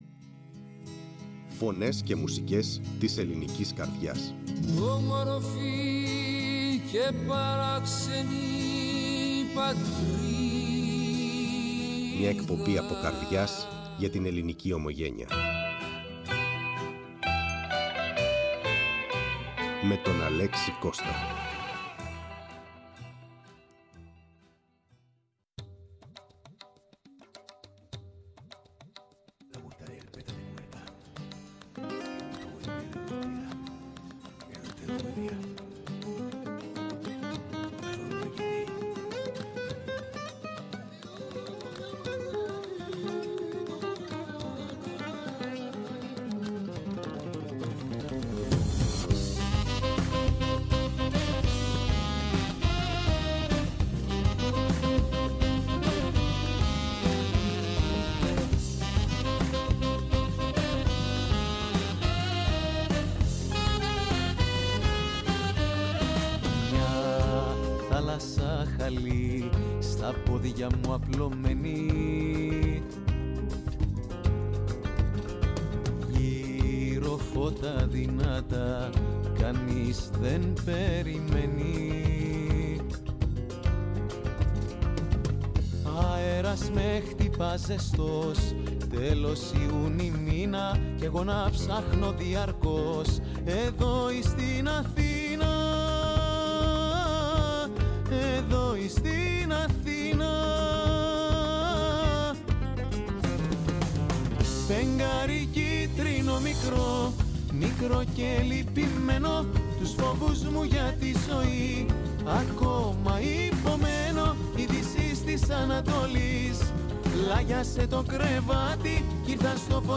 Η Ελλάδα για εμάς είναι παντού τριγύρω απλώς είμαστε στην καρδιά της Ανατολής» Η ΦΩΝΗ ΤΗΣ ΕΛΛΑΔΑΣ Φωνες και Μουσικες ΜΟΥΣΙΚΗ Μουσική ΟΜΟΓΕΝΕΙΑ ΣΥΝΕΝΤΕΥΞΕΙΣ Συνεντεύξεις Ντουμπαι τραγουδοποιος